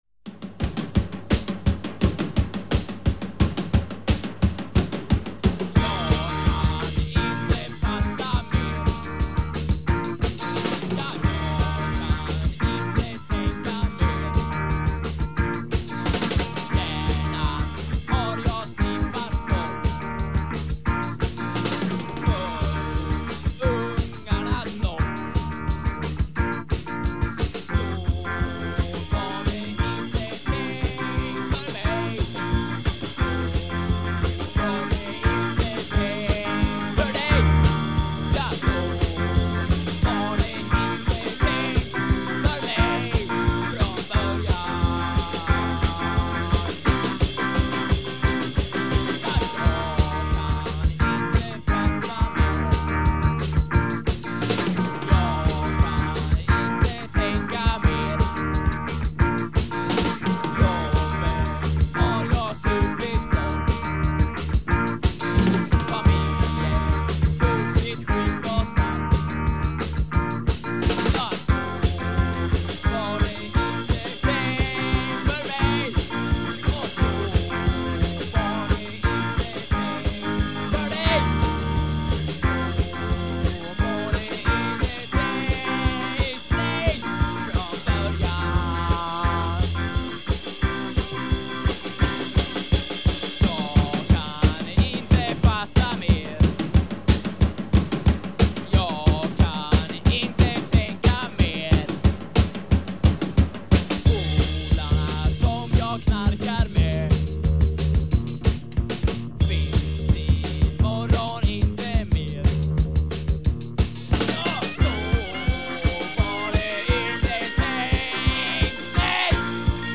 Guitar
Voice
Bass
Drums
Keyboards
Recorded in Blästadgården and at some basements in Vidingsjö